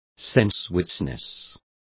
Complete with pronunciation of the translation of sensuousness.